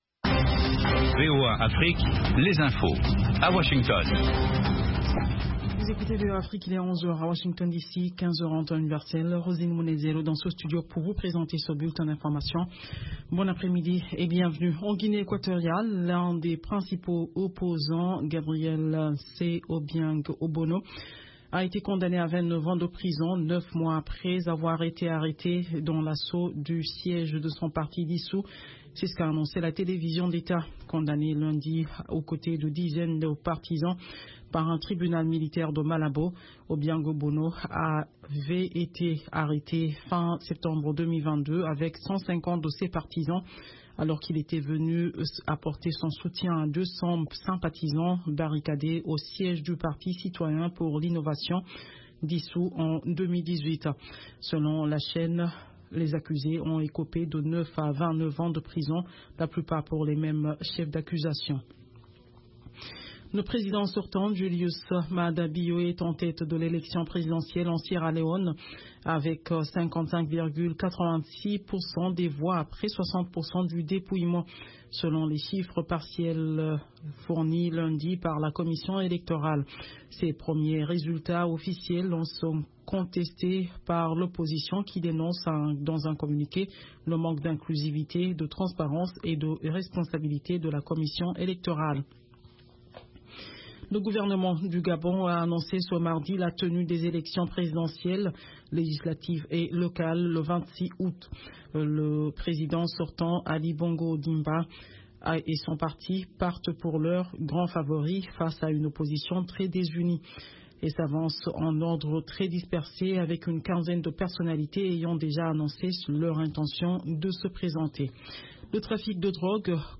Bulletin d’information de 19 heures